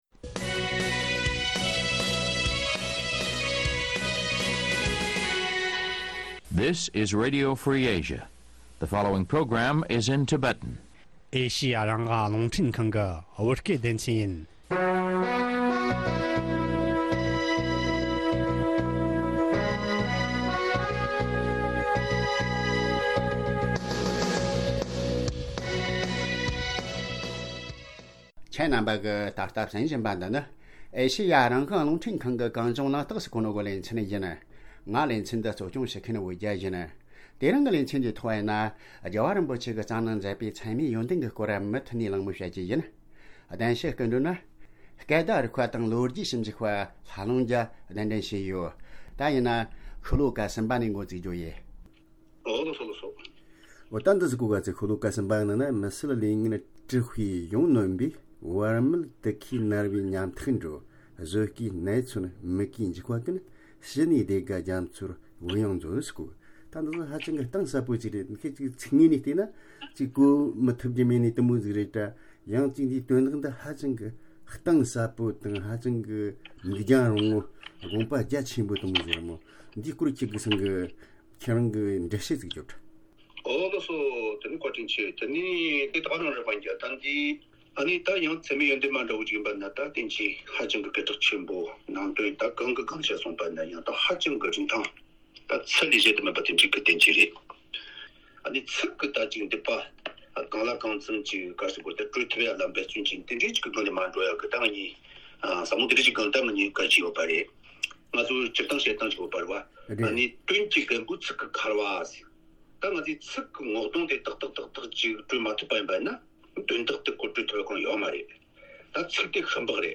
བདེན་གསོལ་སྨོན་ཚིག་ཅེས་པའི་བསྟན་བཅོས་ཀྱི་དགོངས་དོན་འགྲེལ་ཚུལ་སྐོར་གླེང་པ།